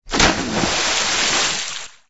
MG_cannon_splash.ogg